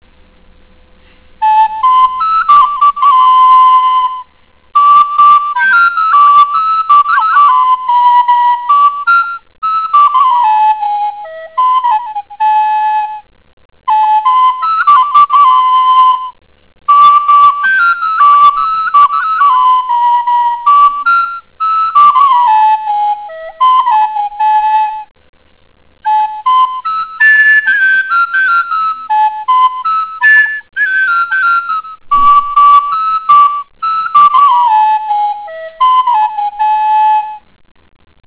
חלילית סופרן:אני